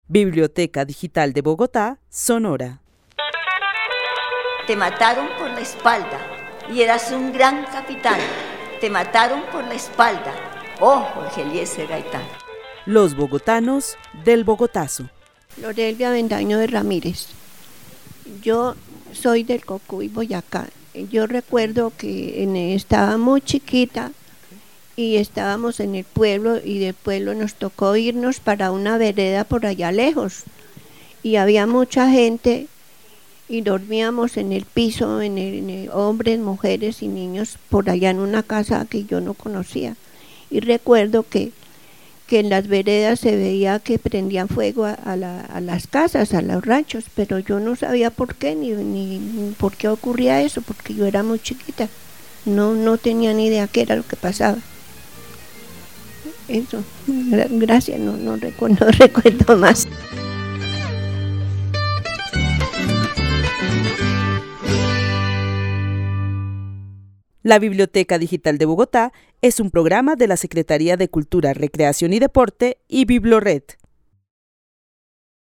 Narración oral de los hechos sucedidos en Bogotá el 9 de abril de 1948.
El testimonio fue grabado en el marco de la actividad "Los bogotanos del Bogotazo" con el club de adultos mayores de la Biblioteca El Tunal.